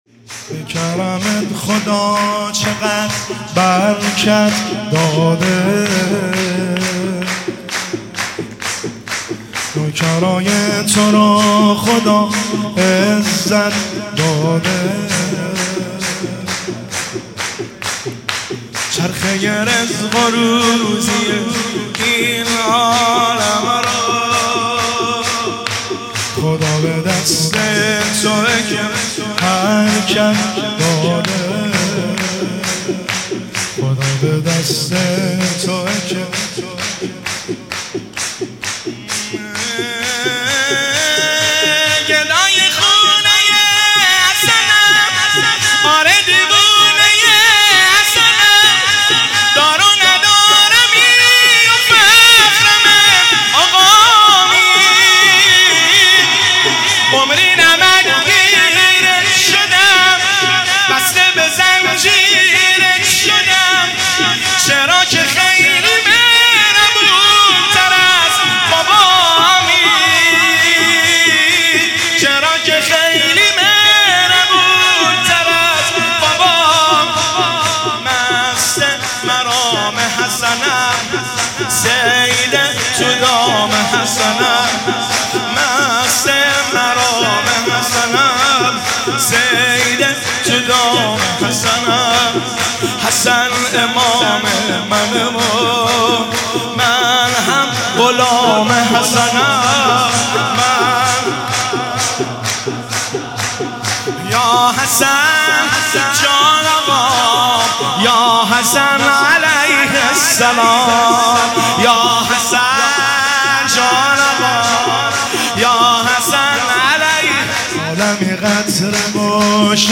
میلاد امام حسن(ع) | هیئت غریب مدینه مازندران 30 اردیبهشت 98